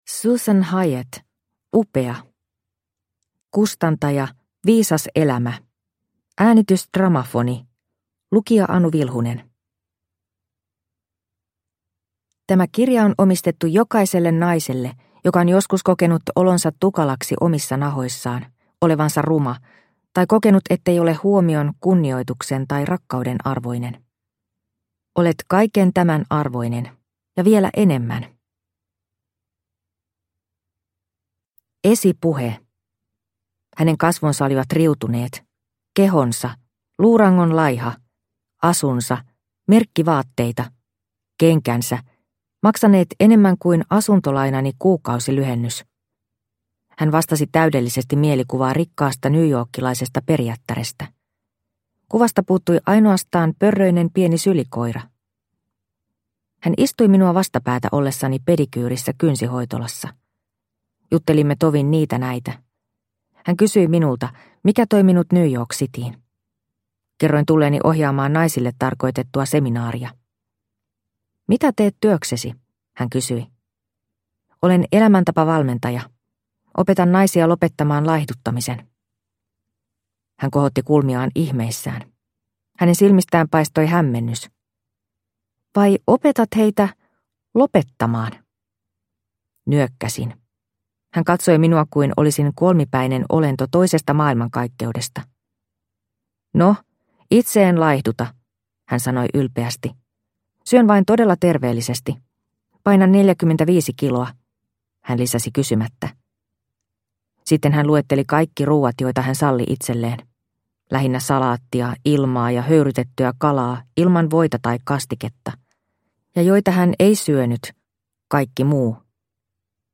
UPEA – Ljudbok – Laddas ner